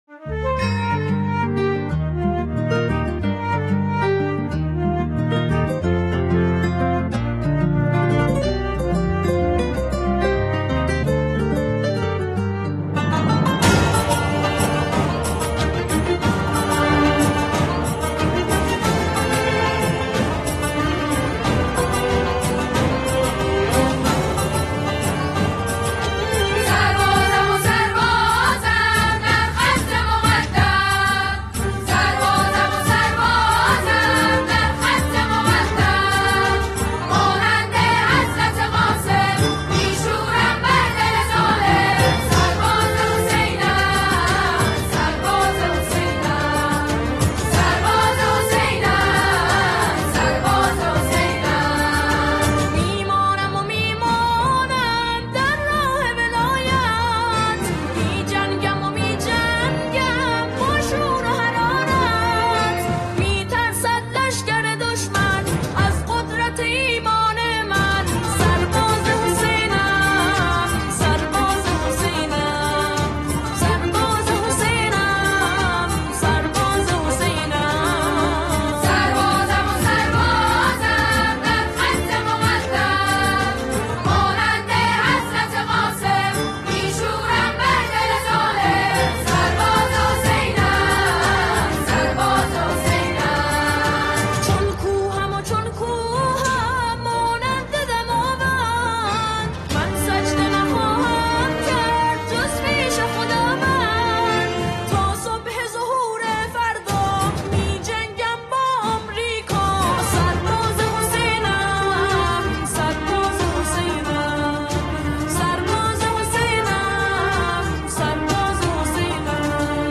زیبا و حماسی